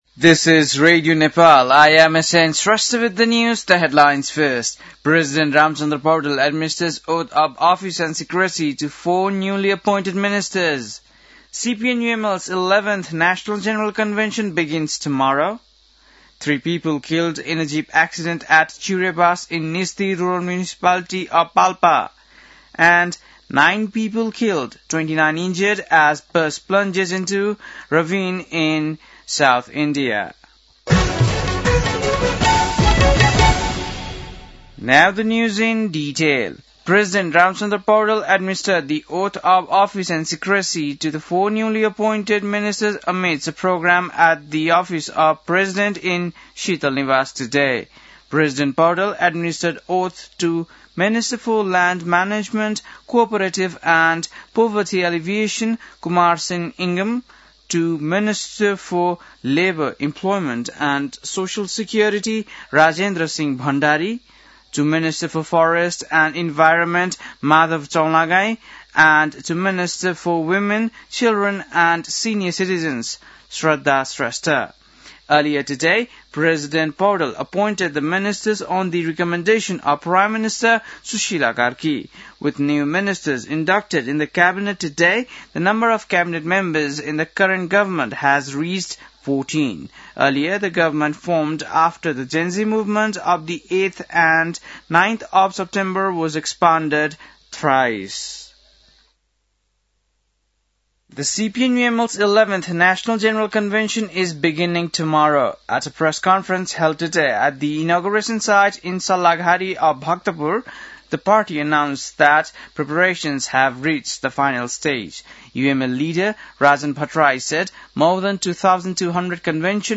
बेलुकी ८ बजेको अङ्ग्रेजी समाचार : २६ मंसिर , २०८२
8-pm-english-news-8-26.mp3